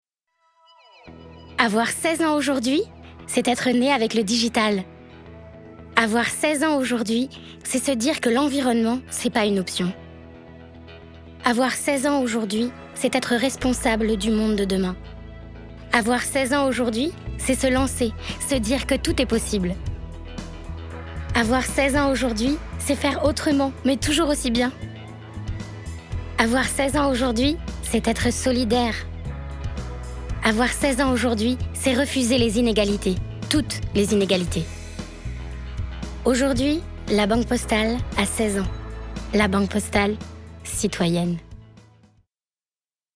démo pub - la banque postale
5 - 35 ans - Mezzo-soprano